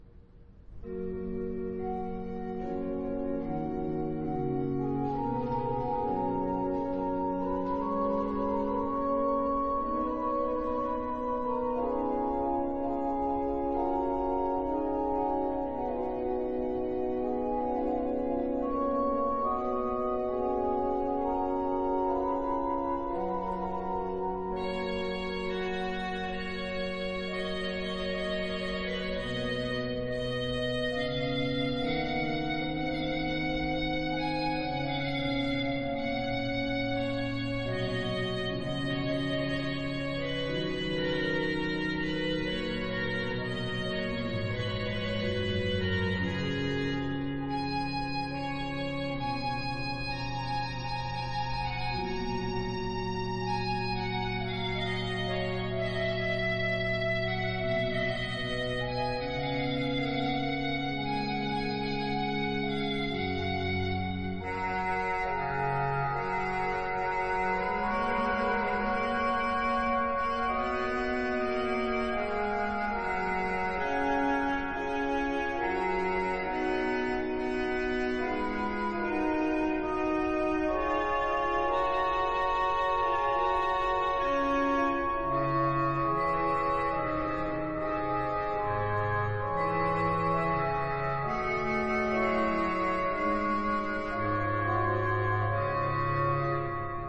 是在凡爾賽宮的皇家禮拜堂的管風琴錄製的。
凡爾賽宮皇家禮拜堂的大管風琴上，聲音華麗。
這張演出由巴黎聖母院的大管風琴座Olivier Latry擔綱。
整個作品，由一曲管風琴一曲人聲合唱，交織而成。
每首曲子都不長，帶著崇高神聖的即興創作，